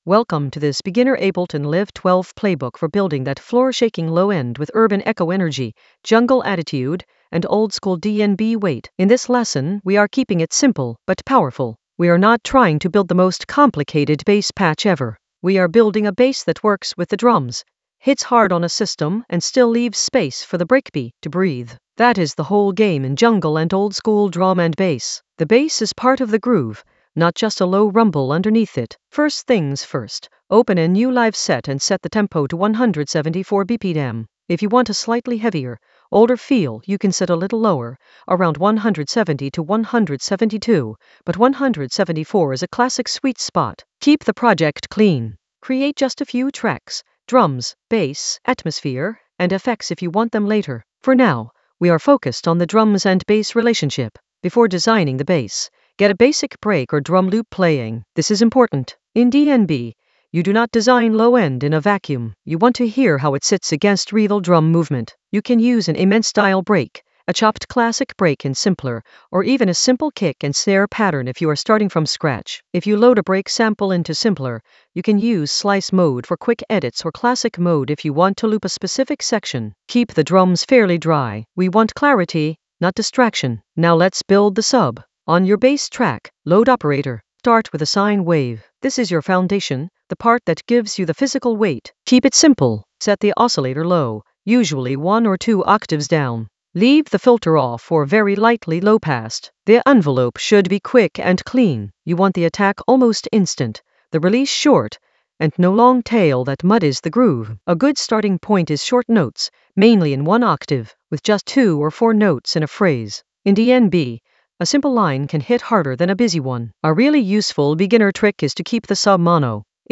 An AI-generated beginner Ableton lesson focused on Urban Echo Ableton Live 12 intro playbook for floor-shaking low end for jungle oldskool DnB vibes in the Sound Design area of drum and bass production.
Narrated lesson audio
The voice track includes the tutorial plus extra teacher commentary.